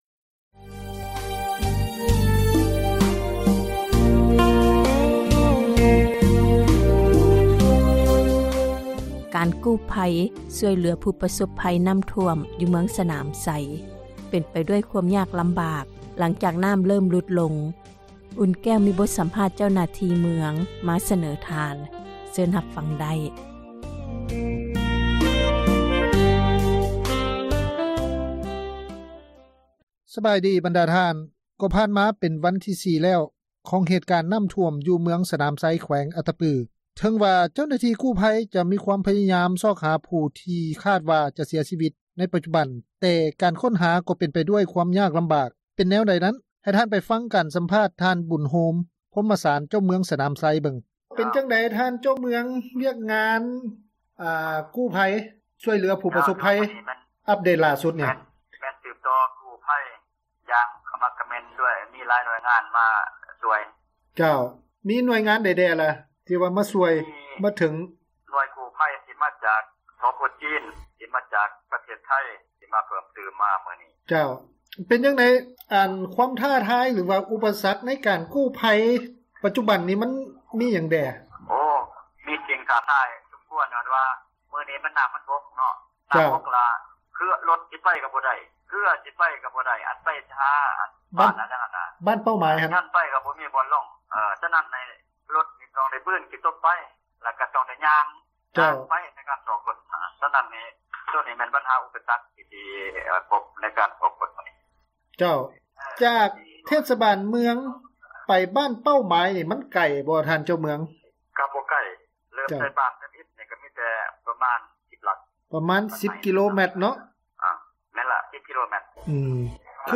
ການສຳພາດ ທ່ານ ບຸນໂຮມ ພົມມະສານ ເຈົ້າເມືອງສະໜາມໄຊ ແຂວງ ອັດຕະປື.